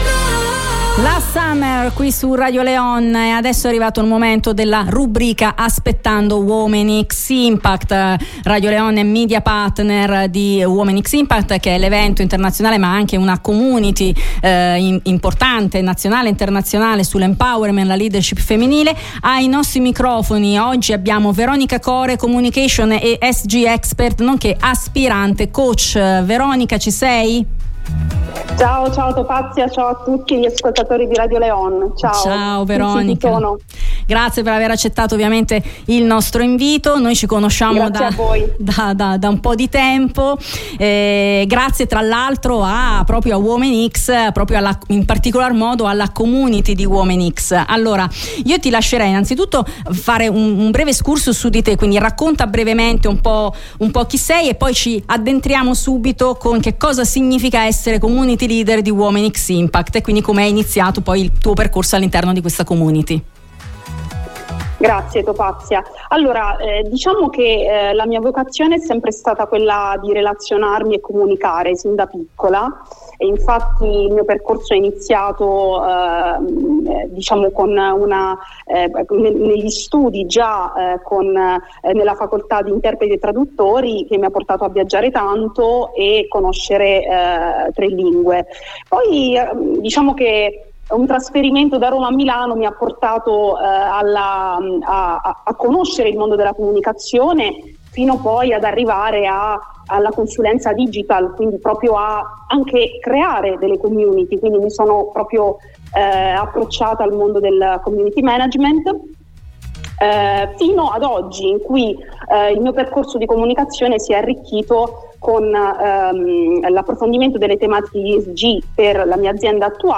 Durante l’intervista